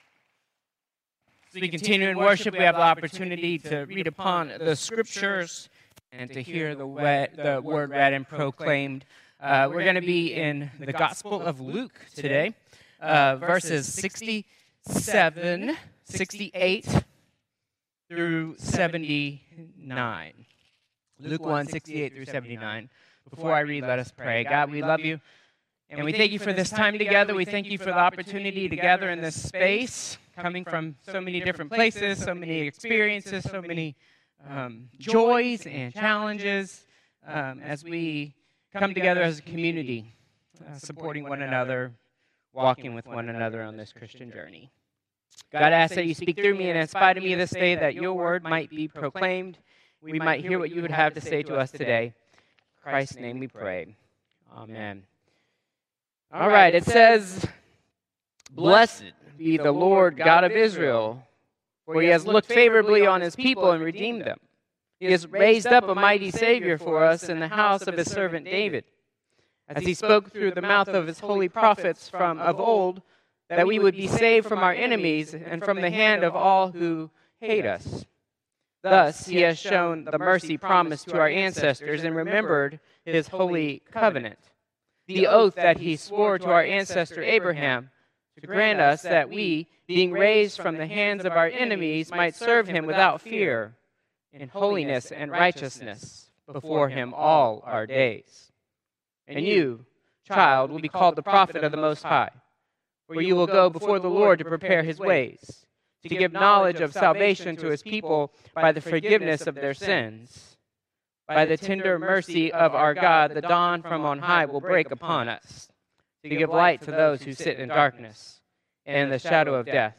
Contemporary Service 12/8/2024